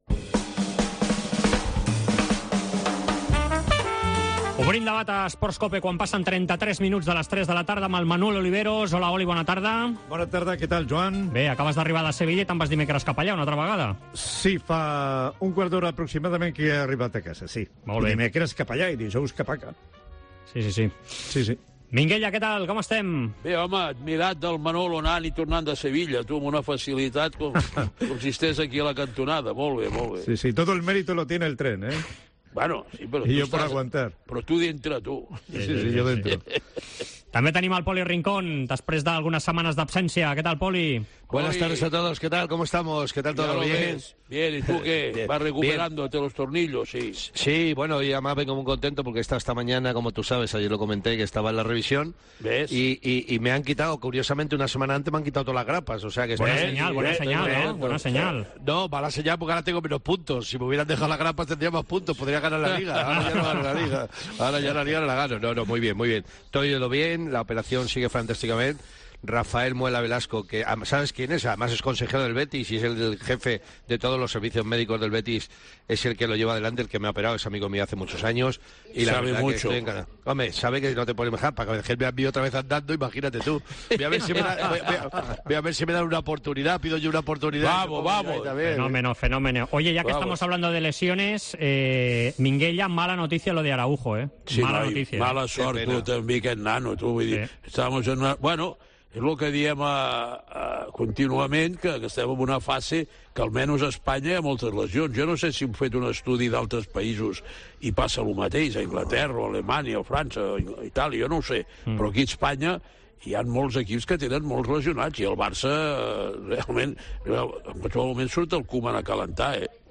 AUDIO: Escolta el 'Debat Esports COPE' amb Josep María Minguella i Poli Rincón discutint sobre els problemes defensius del Barça i les moltes baixes...